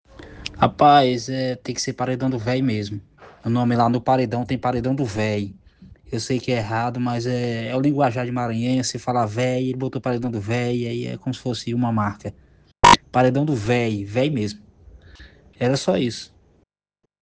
09/03/2026 17:42:09: Enviado pelo locutor - Encaminhado ao cliente